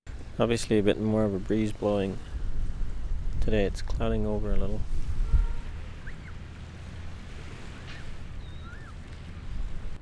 WORLD SOUNDSCAPE PROJECT TAPE LIBRARY
13. tape ID